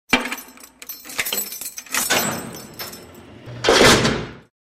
На этой странице собраны звуки тюрьмы — от хлопающих дверей камер до приглушенных разговоров в коридорах.